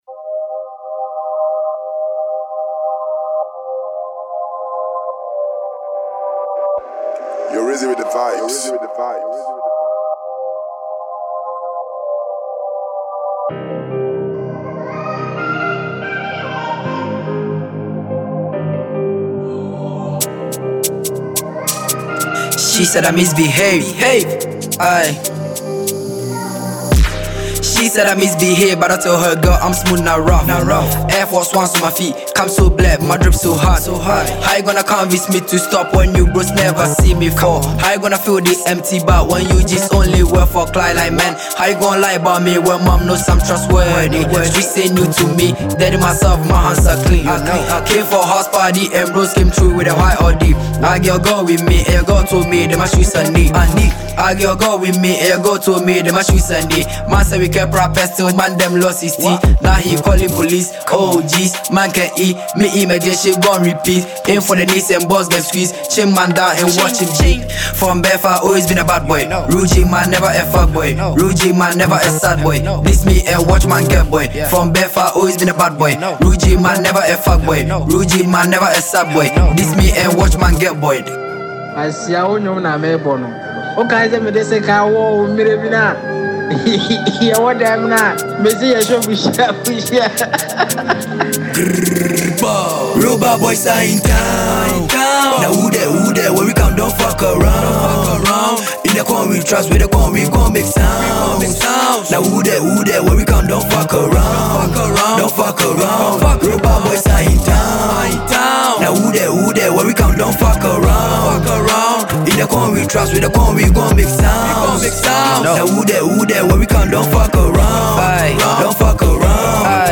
Ghana Music Music
Ghanaian rapper